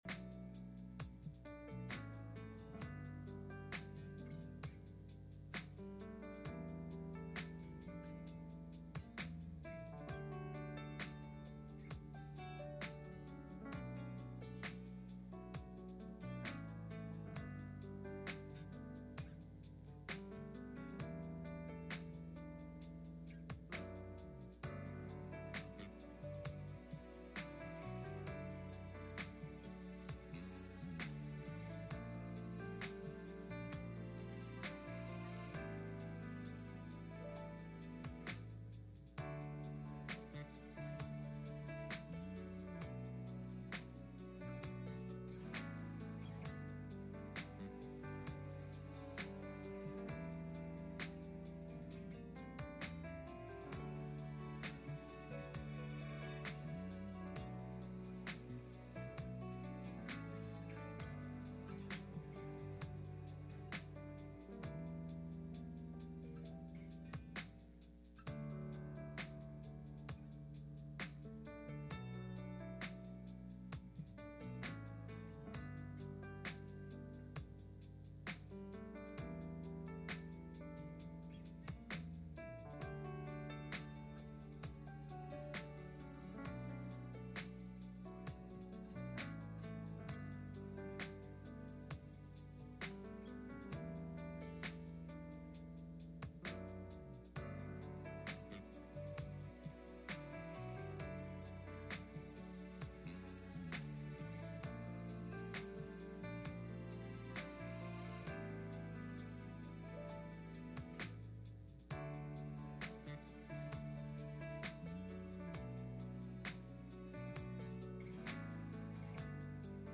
Passage: Daniel 3:16-18 Service Type: Sunday Morning